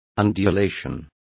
Also find out how ondulacion is pronounced correctly.